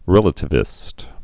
(rĕlə-tĭ-vĭst)